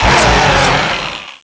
Cri de Minotaupe dans Pokémon Noir et Blanc.